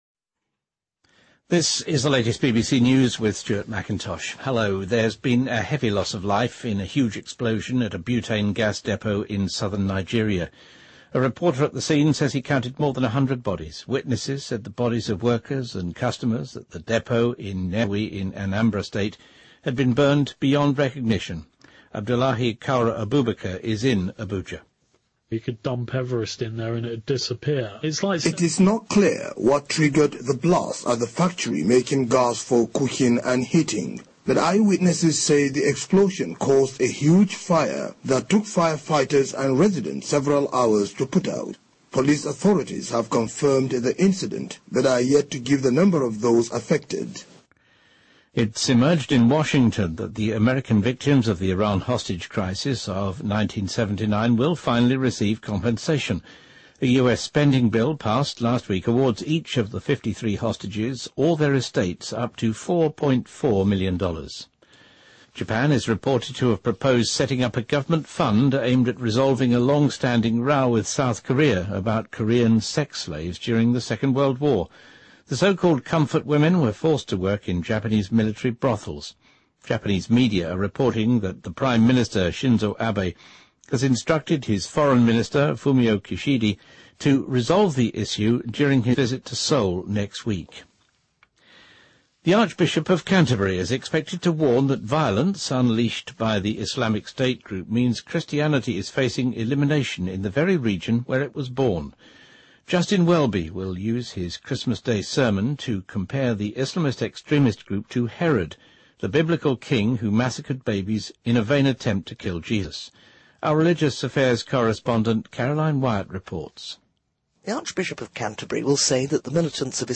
BBC news,日韩就"慰安妇"问题达成协议